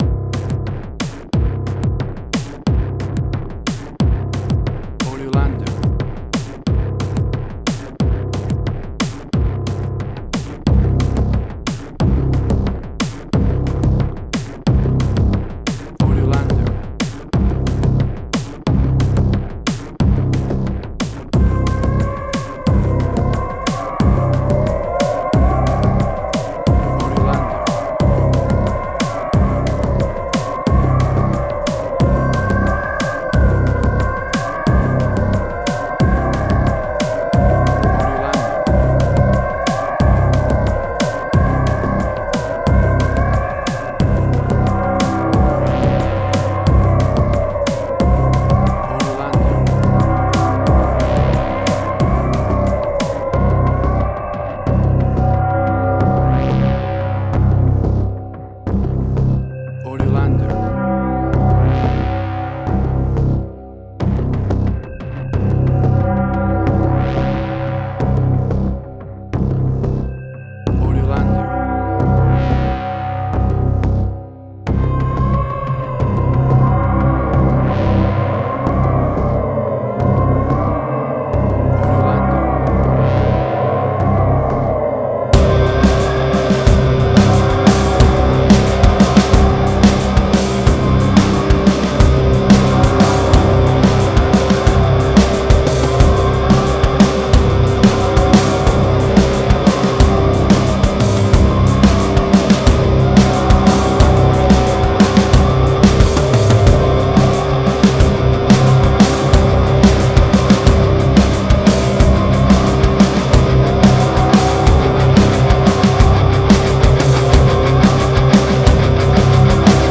Cinematic Industrial Sci-fi.
WAV Sample Rate: 16-Bit stereo, 44.1 kHz
Tempo (BPM): 90